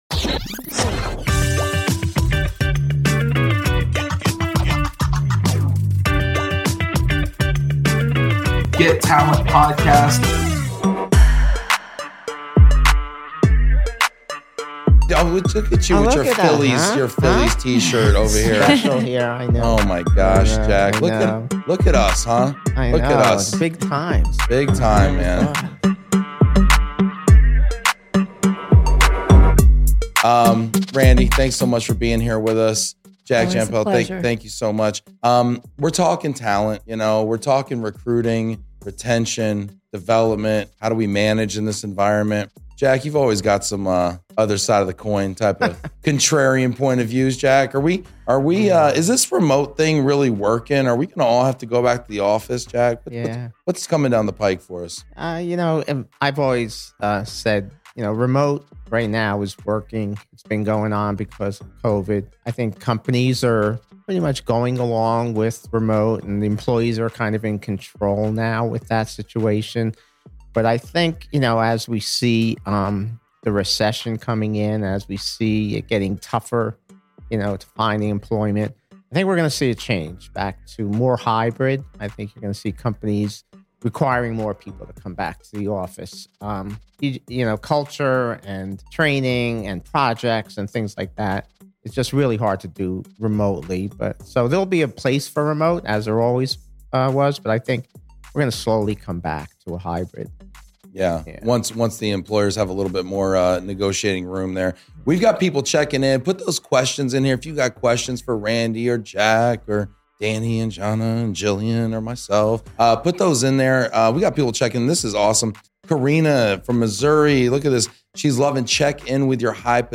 GET TALENT! | #17 | LIVE FROM BLUE WIRE STUDIO